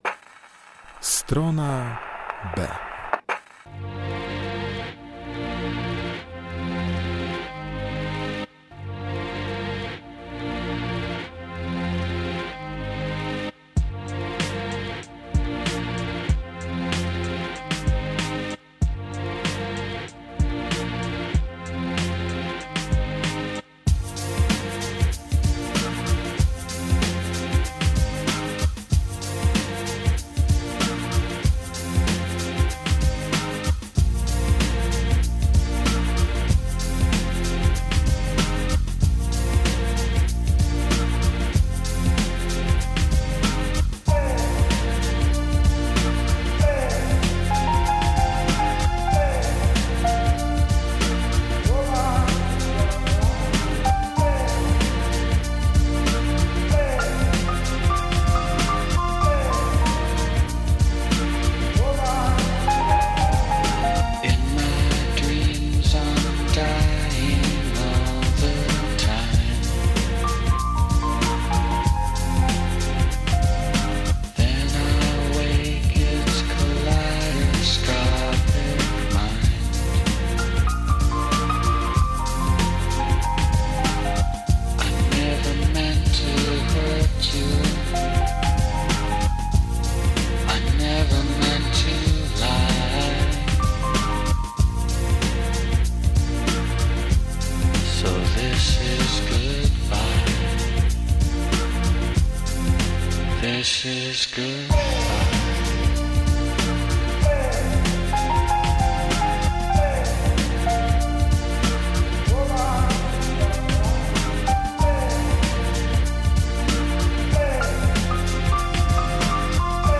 W Stronie B tym razem wyciszenie i refleksja - muzyczne towarzystwo dla wspomnień bliskich.